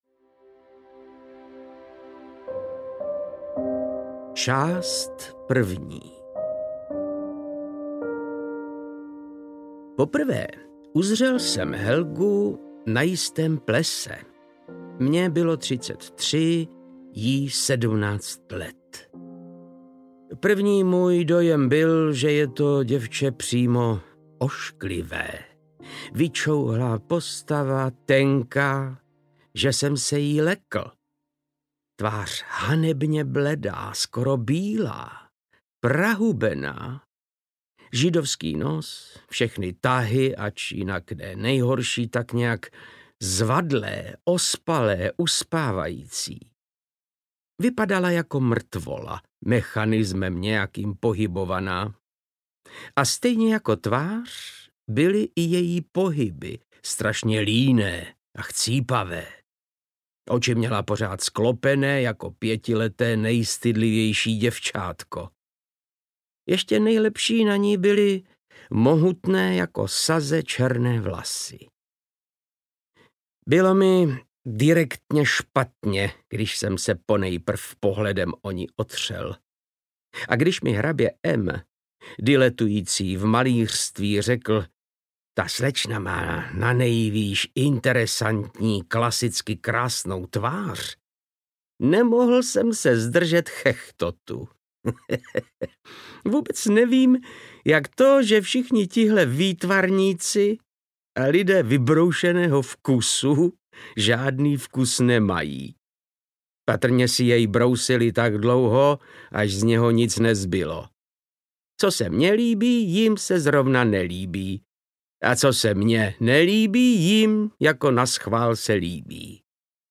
Utrpení knížete Sternenhocha audiokniha
Ukázka z knihy
• InterpretVáclav Knop